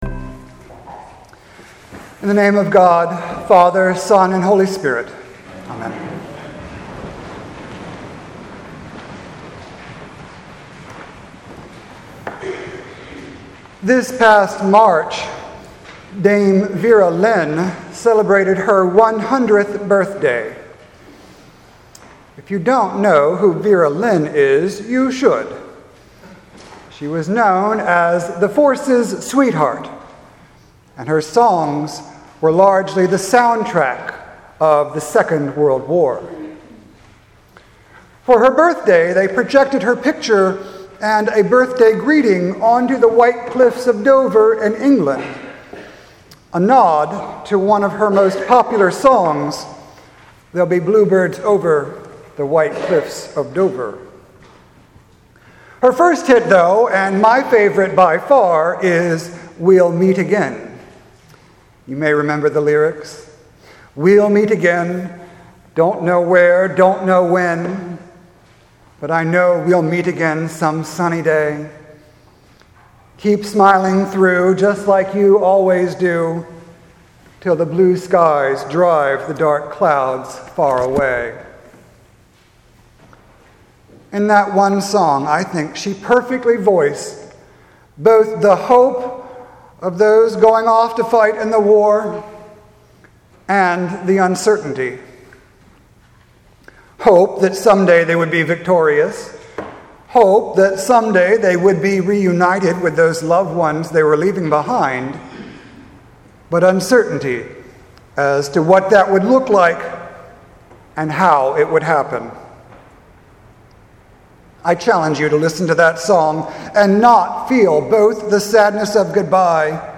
Sermon for Remembrance Sunday November 12th, 2017 Readings: 1 Thessalonians 4:13-18 Matthew 25:1-13 This past March, Dame Vera Lynn celebrated her 100th birthday.